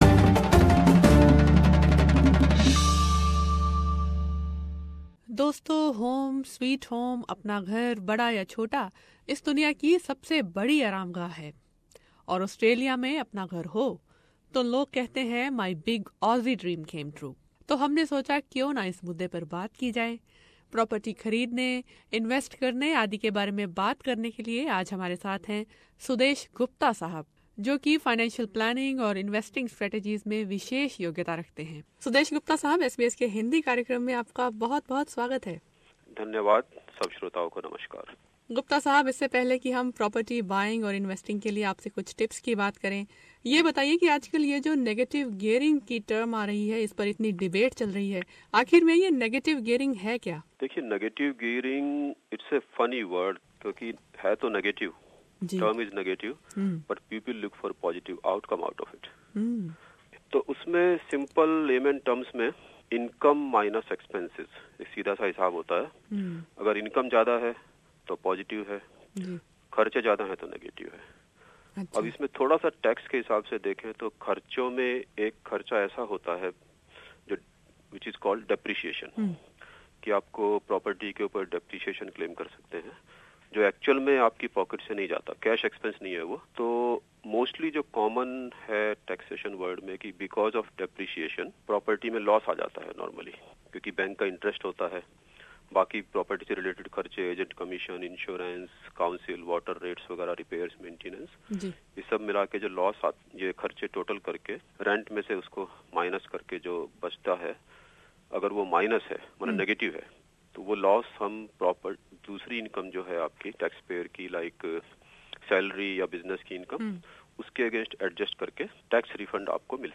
Here is some basic information on negative gearing and interest rates which could help in making the wise decision. SBS Hindi in conversation